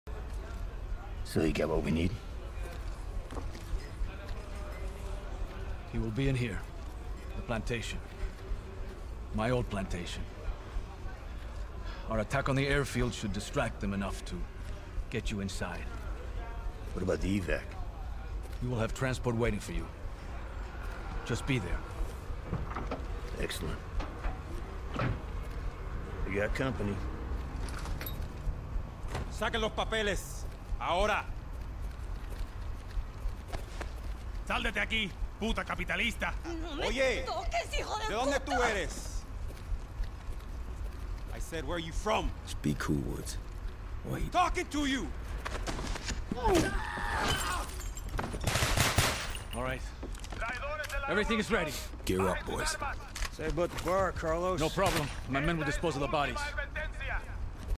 No commentary, just pure nostalgia and action.